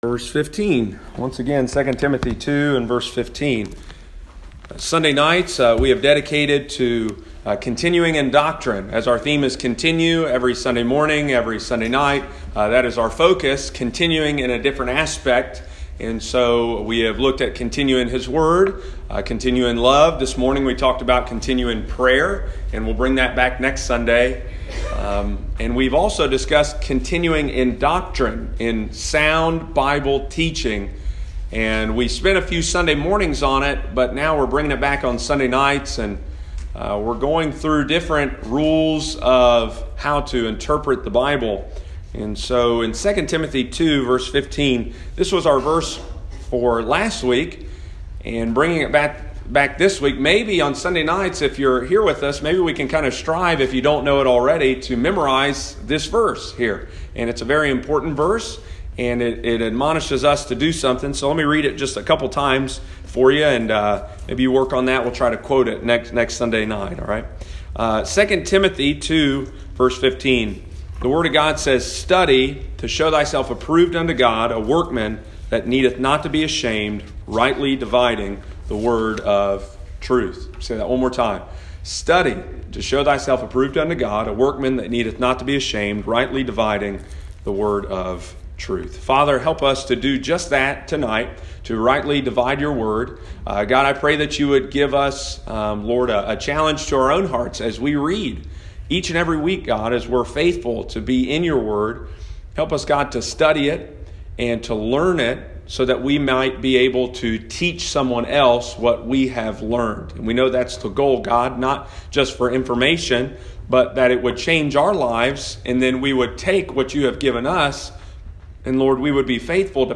Sunday evening, February 2, 2020.